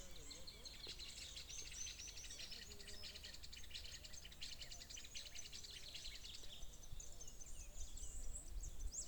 Greater Wagtail-Tyrant (Stigmatura budytoides)
Country: Argentina
Location or protected area: Amaicha del Valle
Condition: Wild
Certainty: Observed, Recorded vocal